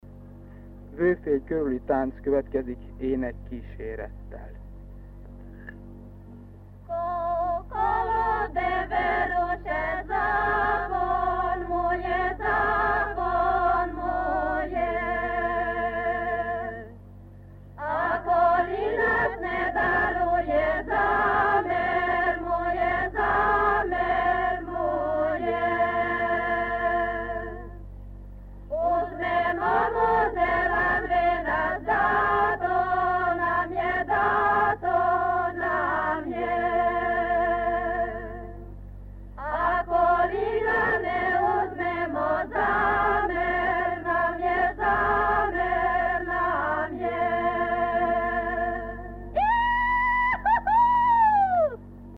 Helység: Lakócsa
Bemondás: Vőfély körüli tánc következik ének kísérettel.
Ezeknek a szekundoknak az elhelyezkedése, az egész kétszólamú faktúrát tekintve, sokkal inkább a szólamvezetésekből létrejövő „átmenő” disszonancia a kísérő és a basszus jellegű alsó-(ellen)szólamok között, és mint ilyen nem tekinthető egy régi, heterofón vagy bordun kétszólamú énekeshagyomány maradványának (amelyet, feltételezéseink szerint a Balkánon, saját anyaországukban ismert a délszlávok e csoportja).